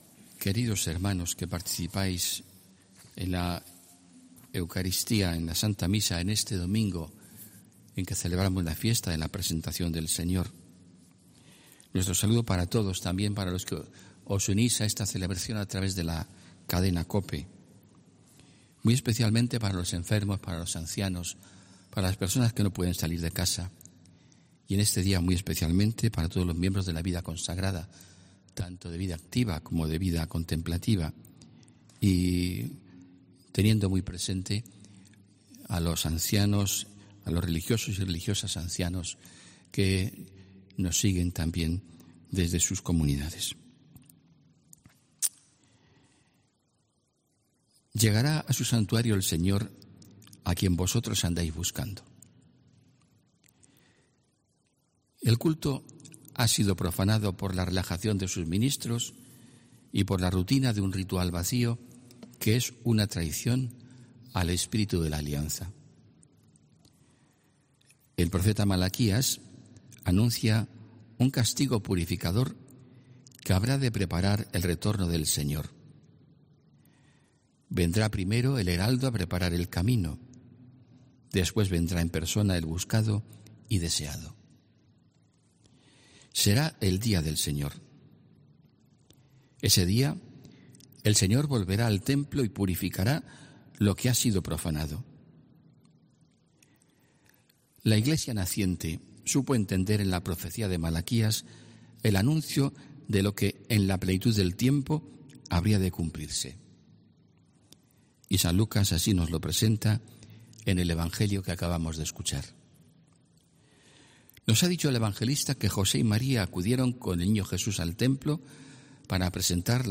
HOMILÍA 2 FEBRERO 2020